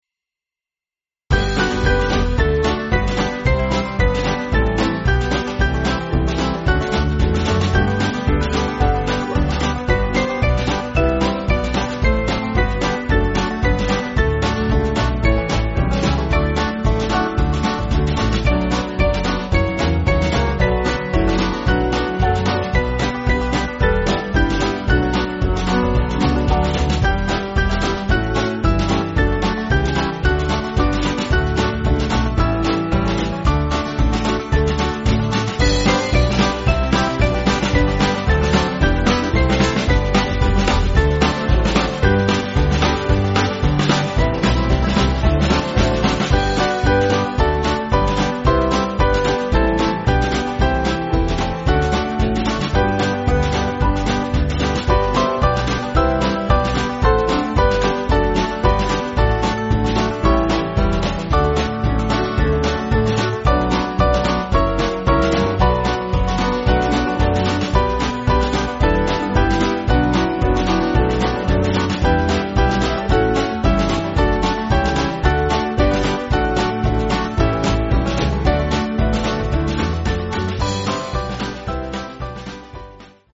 Small Band
(CM)   6/G